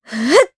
Artemia-Vox_Casting2_jp.wav